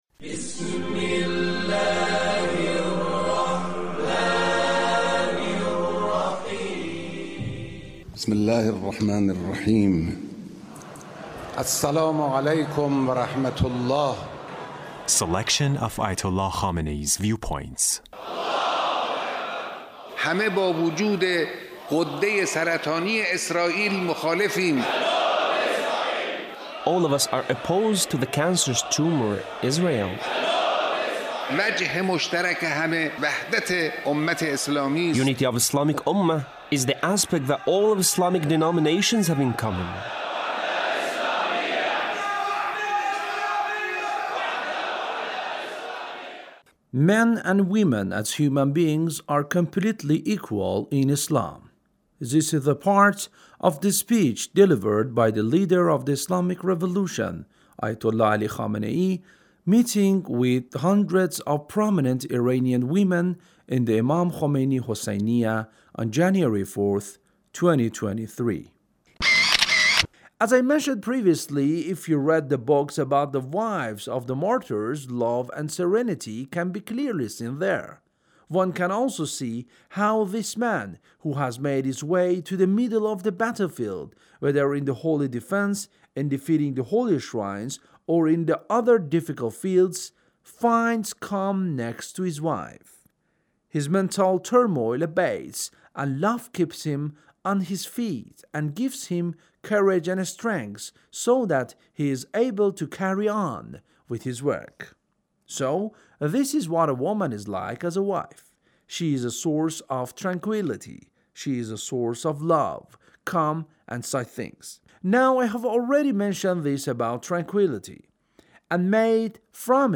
Leader's Speech (1615)